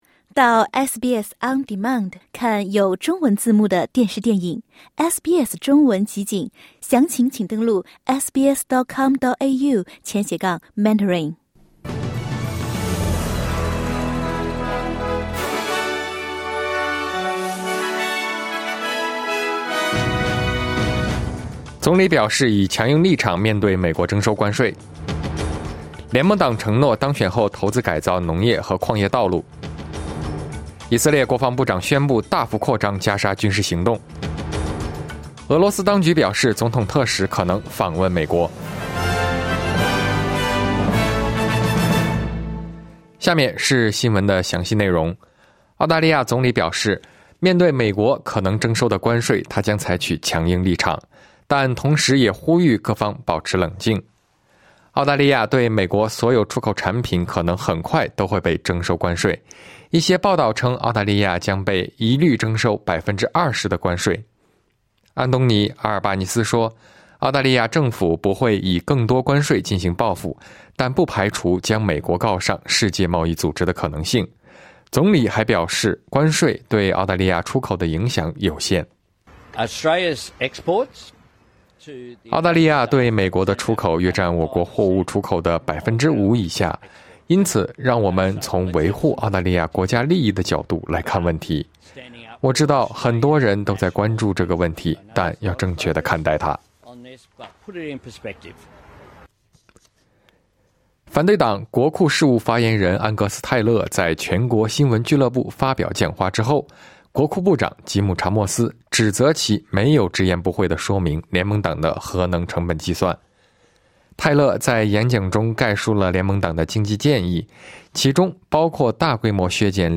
SBS早新闻（2025年4月3日）